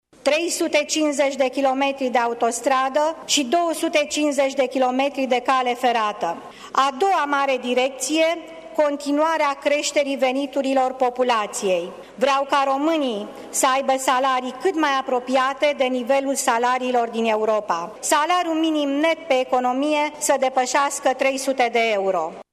După ședința din plenul Parlamentului unde noul Guvern a primit 282 de voturi „pentru”, 136 de voturi „împotrivă” și s-a consemnat o abținere, noul premier a reiterat câteva puncte din programul său de guvernare.